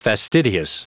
Prononciation du mot fastidious en anglais (fichier audio)
Prononciation du mot : fastidious